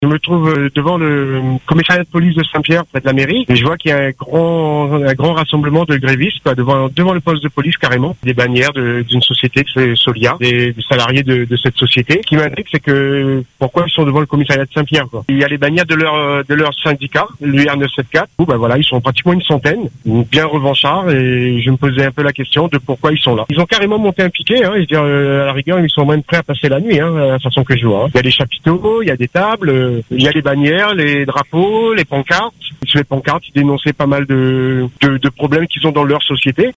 Ce mercredi 26 octobre 2022,  une centaine de salariés se sont retrouvés devant le commissariat de St Pierre pour dénoncer les problèmes qu’ils rencontrent dans leur société comme en témoigne notre auditeur dans le Podcast ci-dessous.